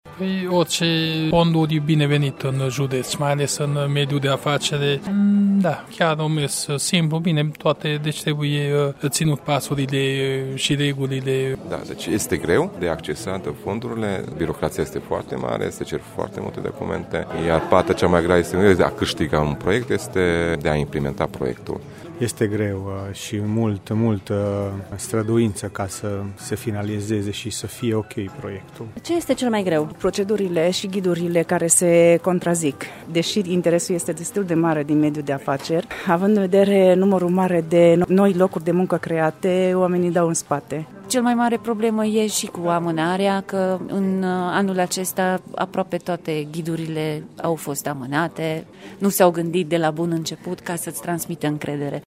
Oamenii de afaceri mureșeni prezenți la evenimentul de lansare al Programului de finanțare Tranziție Justă, sunt sceptici că noile fonduri vor fi mai ușor de accesat și implementat ca restul fondurilor europene: